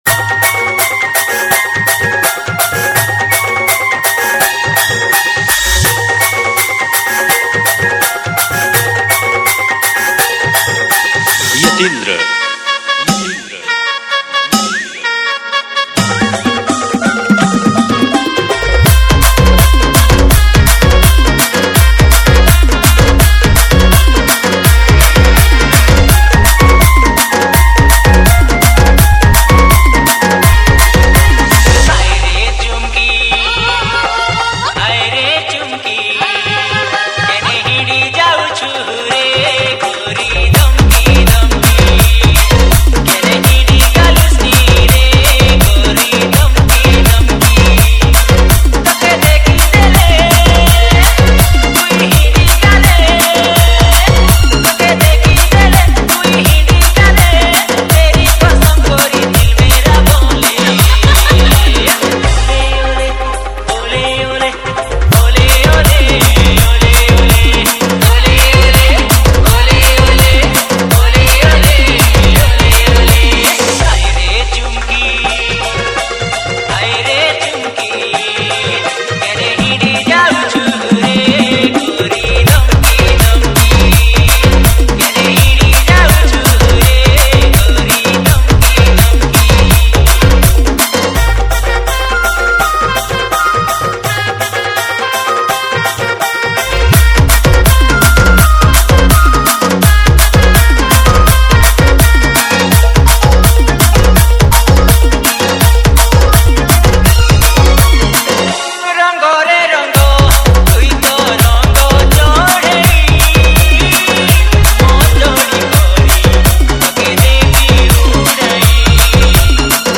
CG LOVE DJ REMIX